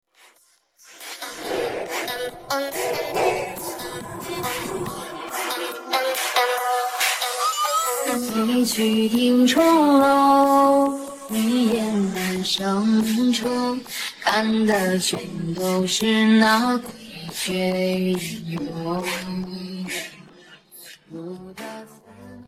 Vokaldel